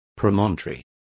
Complete with pronunciation of the translation of promontories.